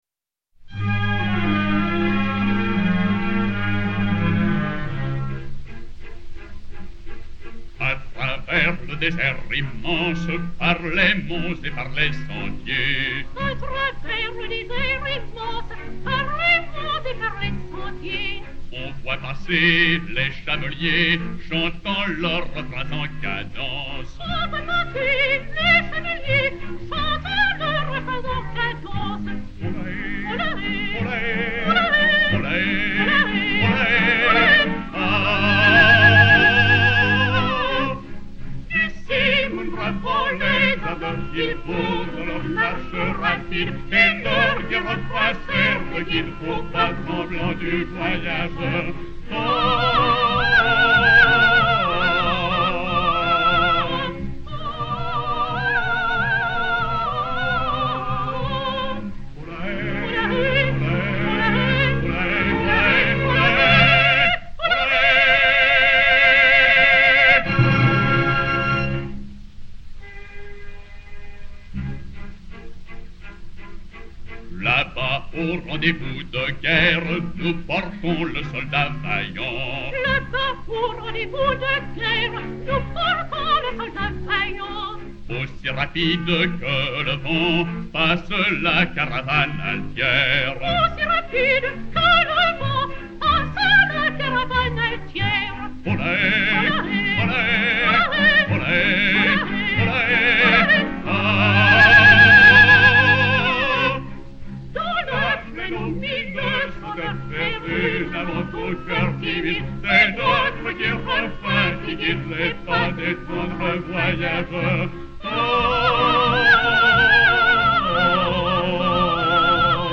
Pathe X 92002 - duo des chameliers.mp3